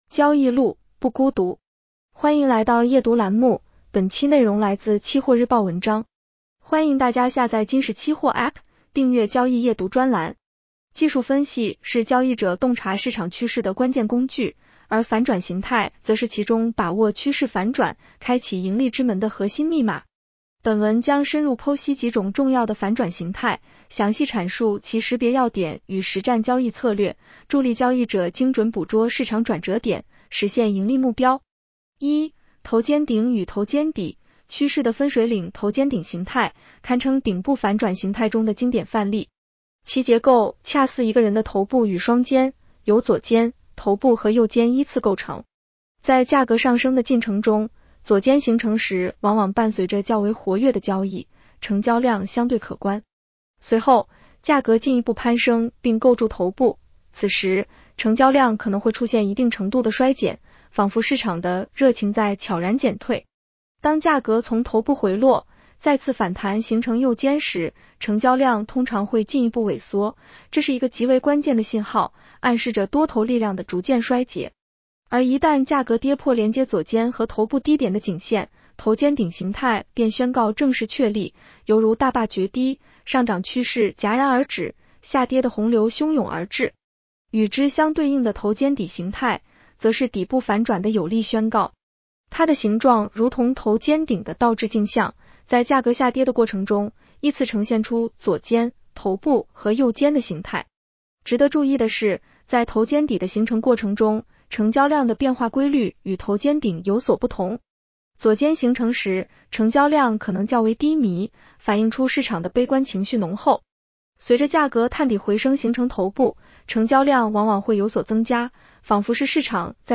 【期货交易夜读音频版】 女声普通话版 下载mp3 技术分析是交易者洞察市场趋势的关键工具，而反转形态则是其中把握趋势反转、开启盈利之门的核心密码。